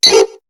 Cri de Roucool dans Pokémon HOME.